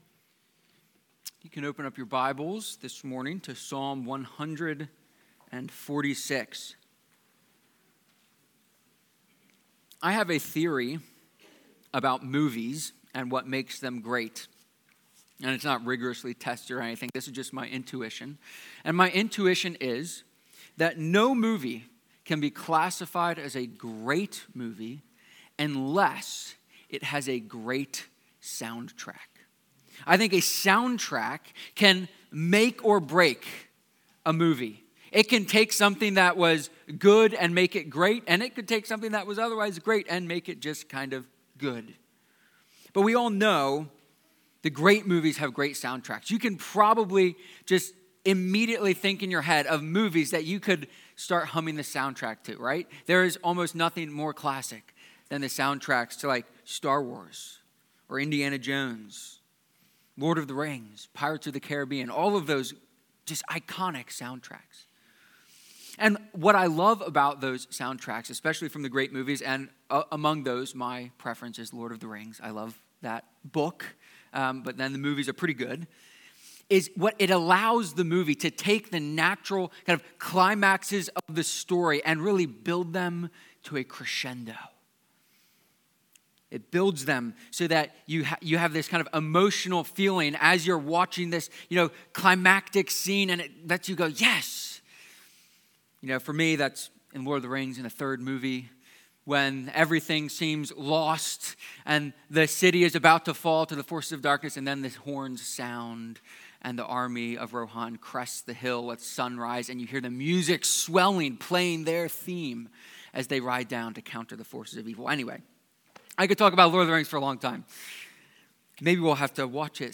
Psalm-146-sermon.mp3